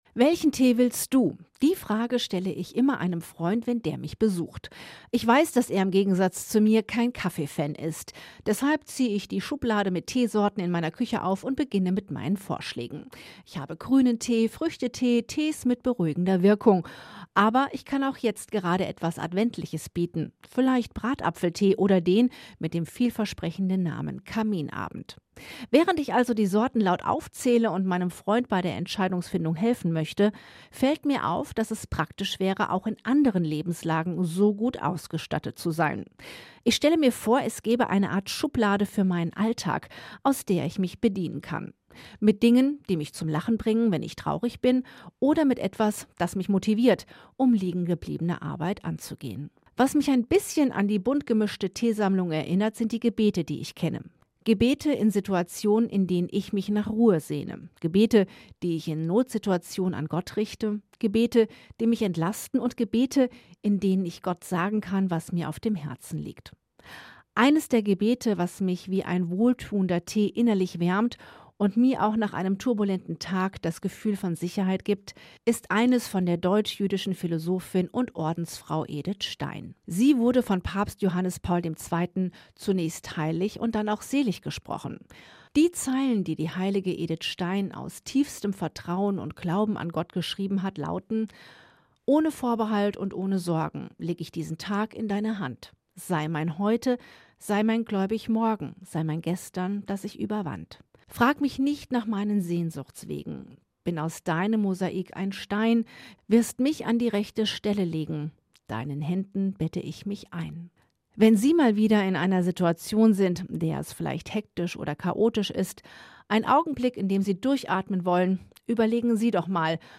gesprochen von Rundfunkredakteurin